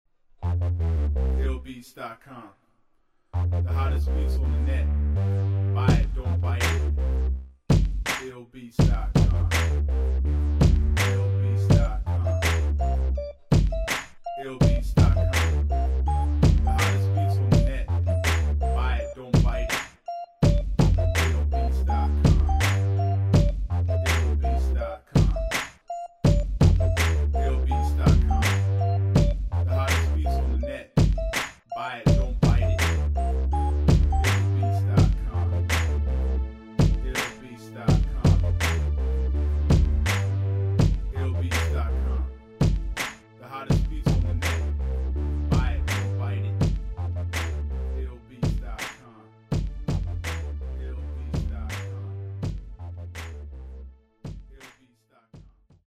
West Coast Rap Beats